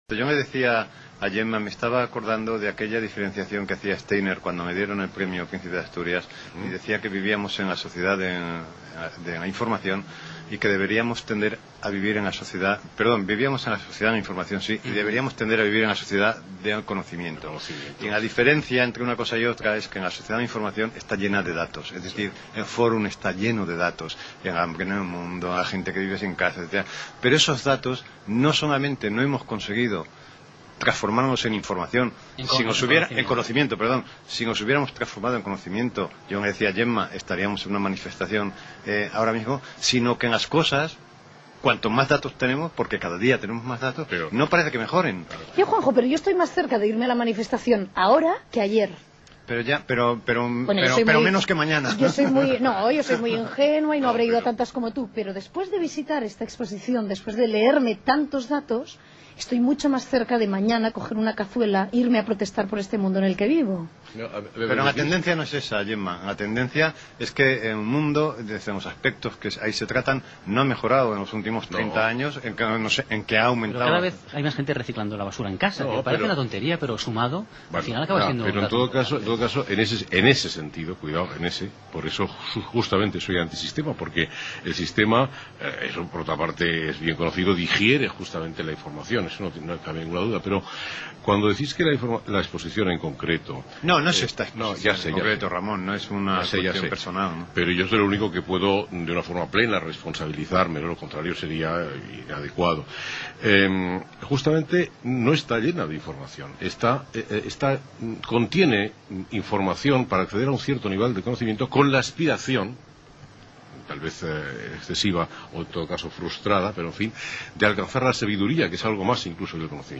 Se trata de un extracto del inicio del programa La Ventana en el que el escritor Juan José Millás realiza unas disertaciones muy interesantes sobre la Sociedad de la Información en contraposición con la del Conocimiento.
entra en debate en la tertulia